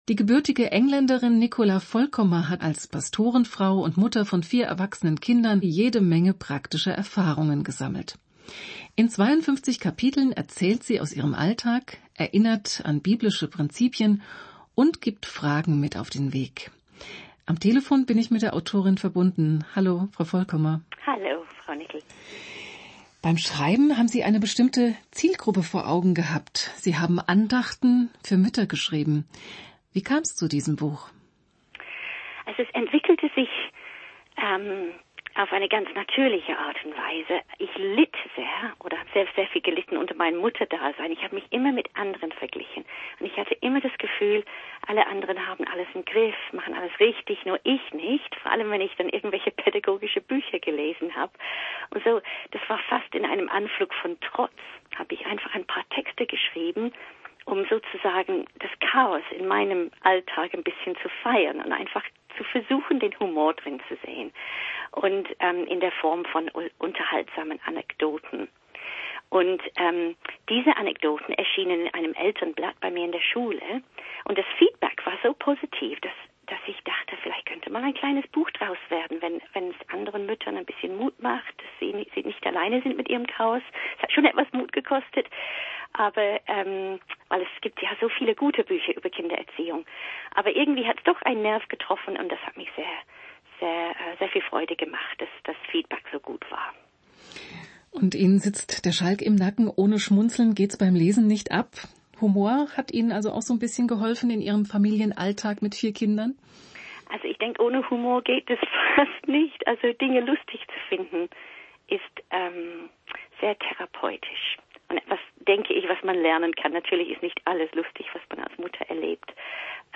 Interview in der ERF-Plus-Radiosendung "aufgeweckt":
interview-muttibuch.mp3